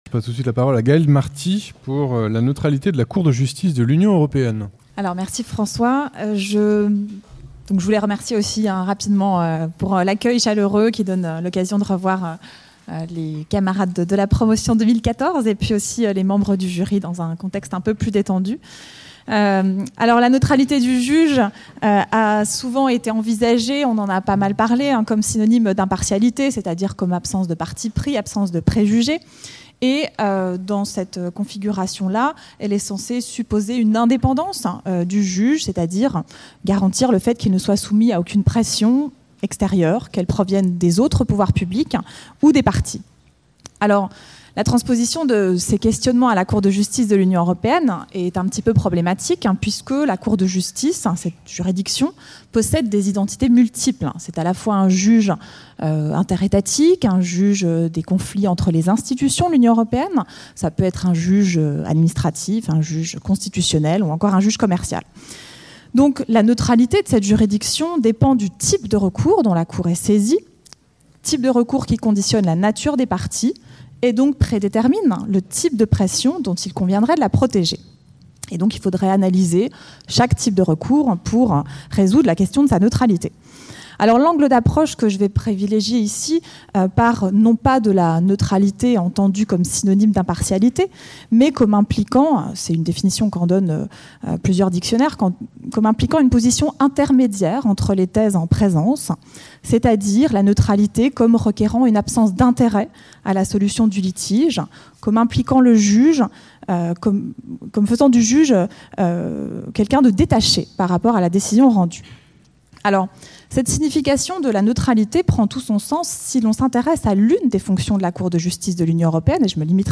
Retour sur le colloque : La neutralité (13 et 14 octobre 2016) Colloque de la promotion 2014 de l'agrégation de droit public Le colloque des 13 et 14 octobre 2016, réunissant les lauréats et membres du jury du concours d’agrégation de droit public 2014, a été l’occasion de mettre en perspective avec succès la neutralité en droit, en interrogeant ses raisons d’être et ses formes, mais en insistant aussi sur ses limites voire ses impasses.